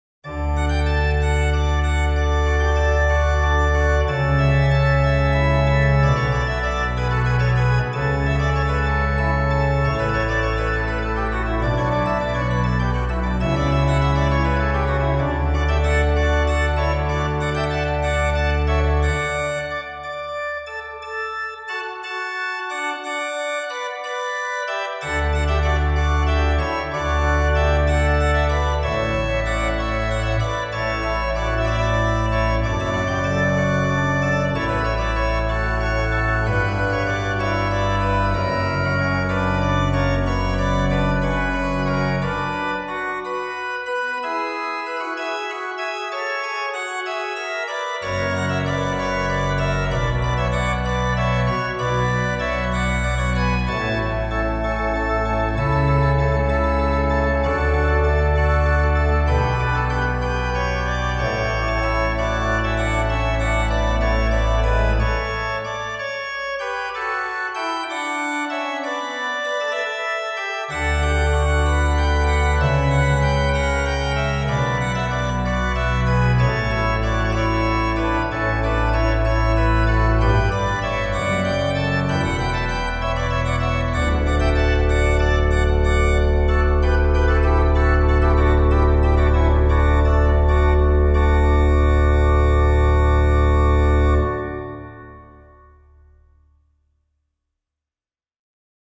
Ce mois-ci (en préparation du mois de décembre), un arrangement de choral avec une mélodie de M. Luther.
Pour faire ressortir clairement cette mélodie, j’ai recouru à la trompette 8.
Les deux jeux d’accompagnement sont assortis d’un cornet : un jeu de mixture aussi fin que possible contre une Sesquilatera. De cette façon, les trois jeux se poursuivent séparément tout en formant une unité.
Ajoutez-y encore un tempérament de Werckmeister et vous obtenez le son Baroque allemand qui - à mon humble avis - était celui que Pachelbel devait suggérer